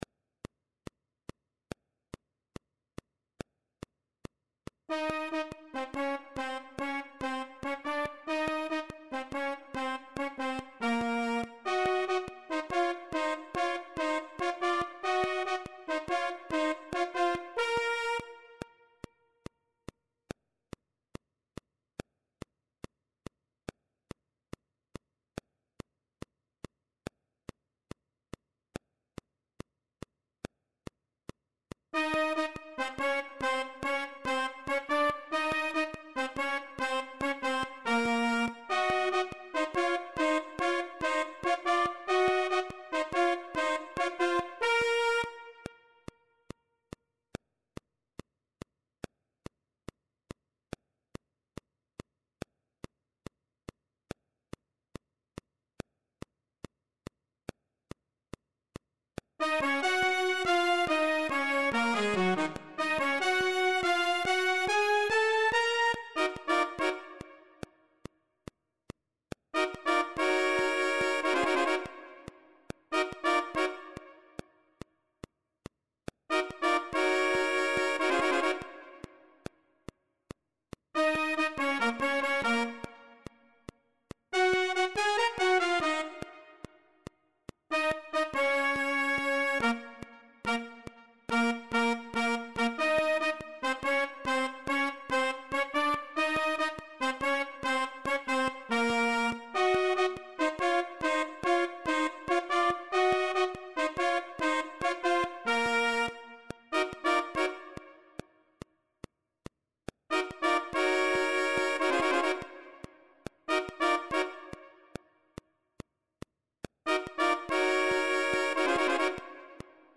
TOM ORIGINAL.
Partitura do Naipe de Metais para os seguintes instrumentos:
1. Sax Tenor;
2. Sax Alto;
3. Trompete; e,
4. Trombone.